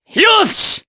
Kasel-Vox_Happy4_jp.wav